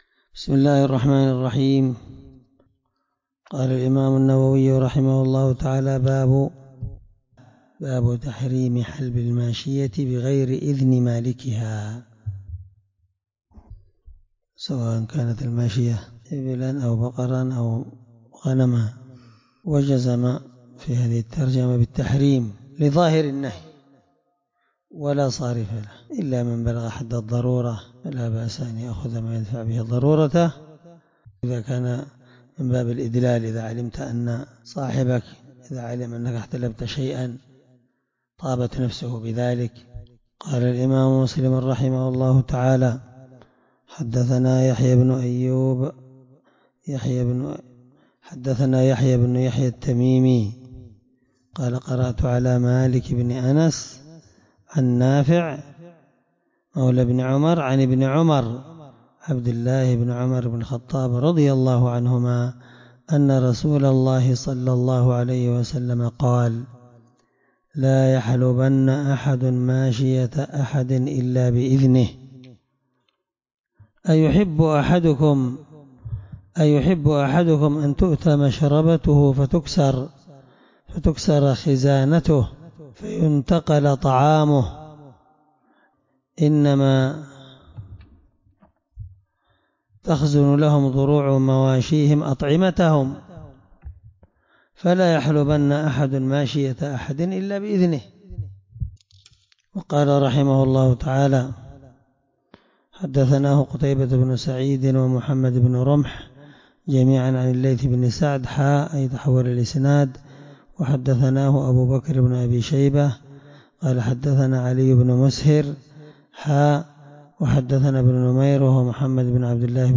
الدرس4من شرح كتاب اللقطة الحدود حديث رقم(1726) من صحيح مسلم